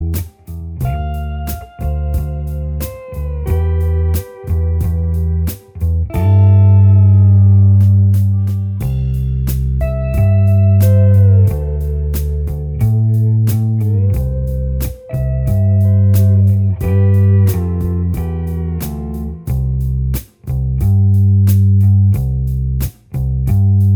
Minus Acoustic Guitar Soft Rock 4:31 Buy £1.50